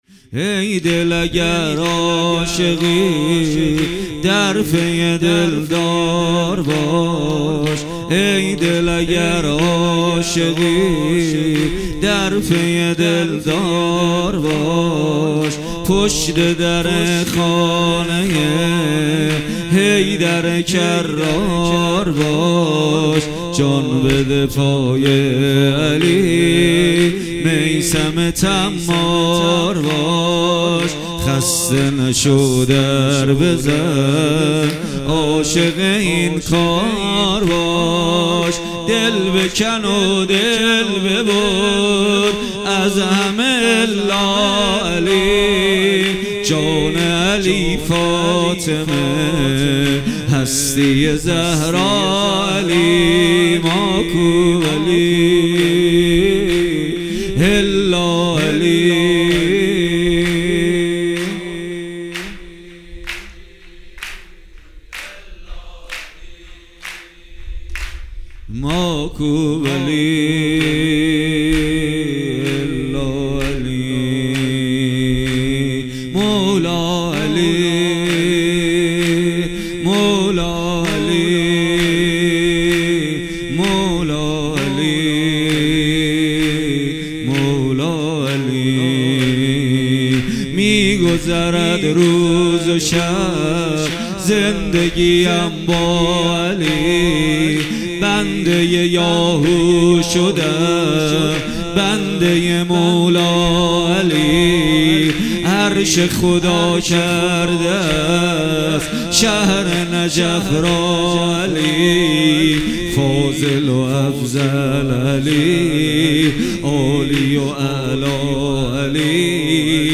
سرود غدیریه
مراسم جشن عید غدیر در هیأت ریحانةالنبی(ص